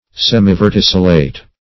Semiverticillate \Sem`i*ver*tic"il*late\, a. Partially verticillate.